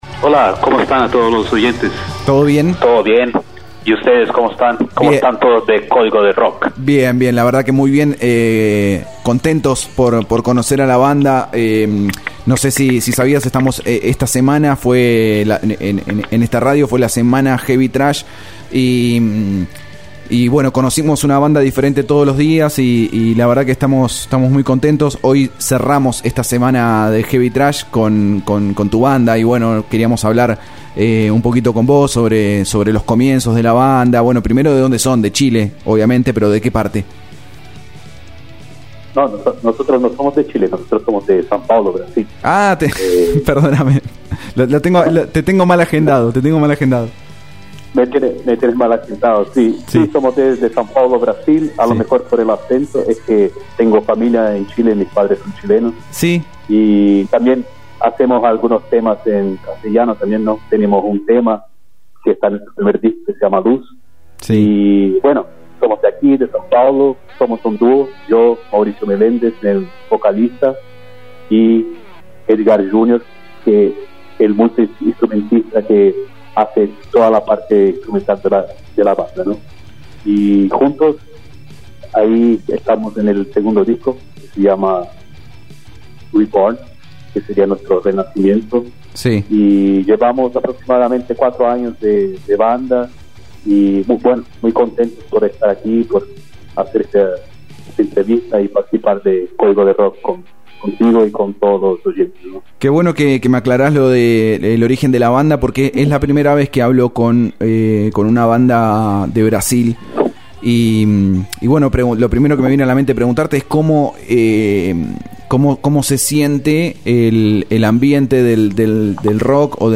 Notas y Entrevistas realizadas en Om Radio